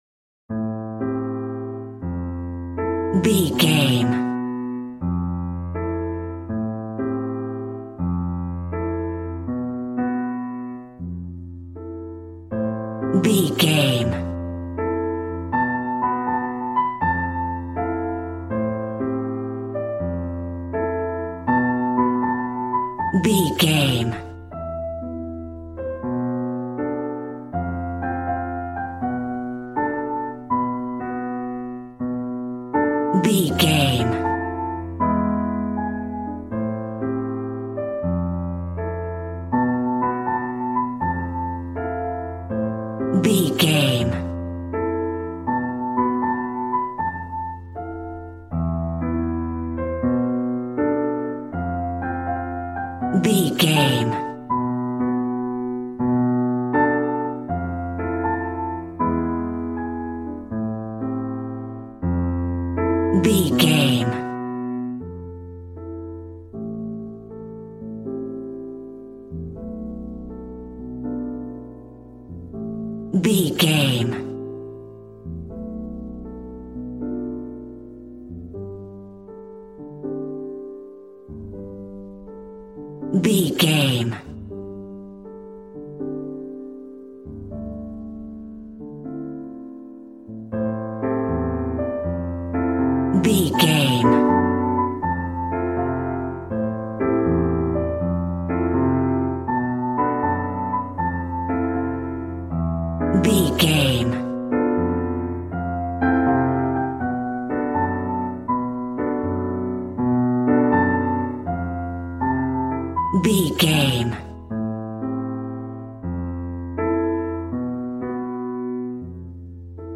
Aeolian/Minor